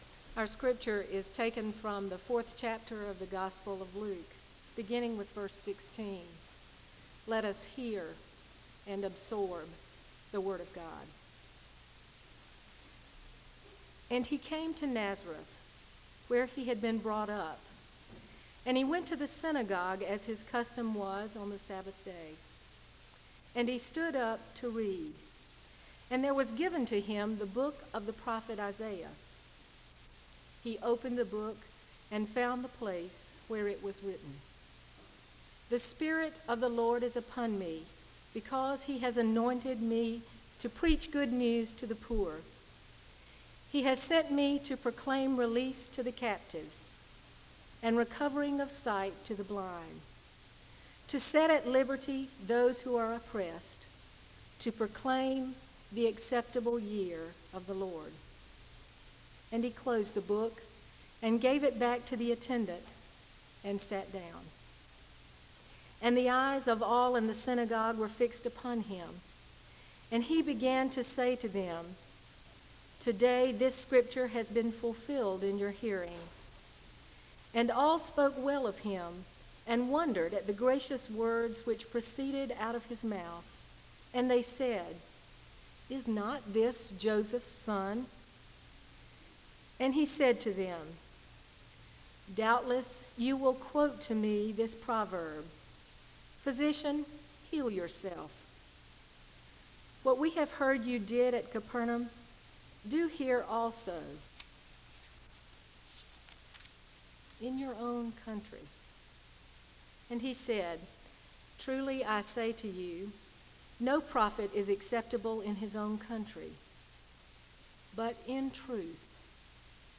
The service begins with a Scripture reading from Luke 4 (0:00-3:07). There is a song of worship (3:08-7:31). Prayer concerns are shared and there is a moment of prayer (7:32-11:09).